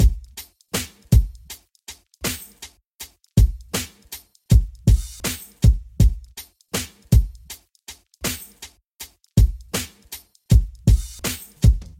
Drum Loops " D3
描述：简单的80bpm鼓循环，带有房间混响。
标签： 节拍 循环